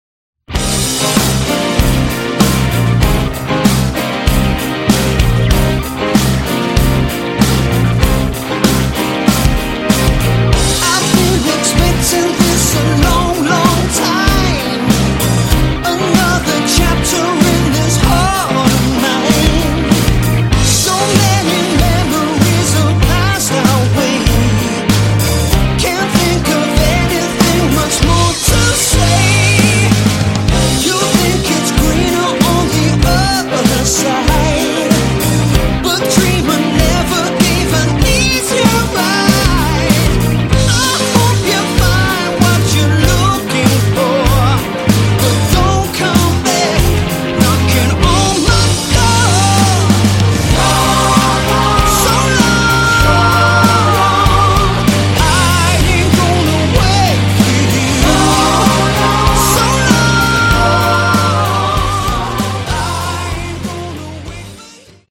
Category: AOR
vocals
lead guitars
drums
bass
keyboards